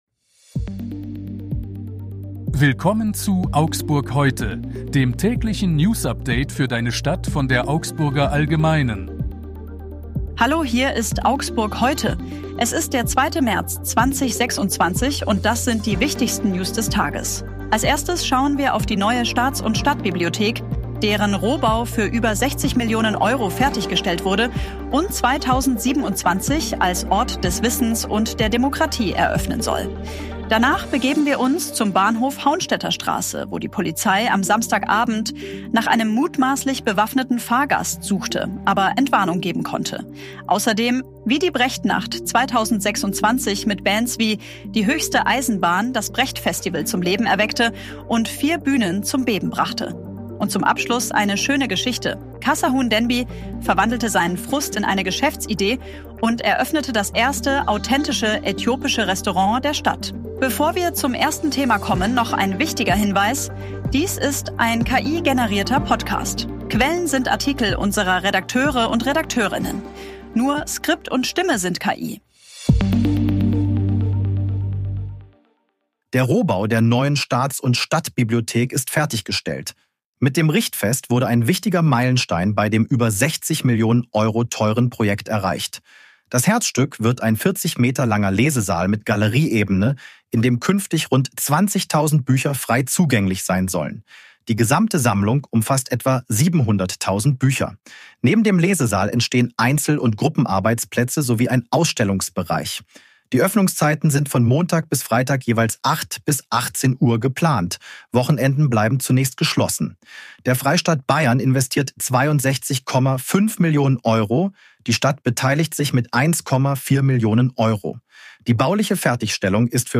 Skript und Stimme sind KI.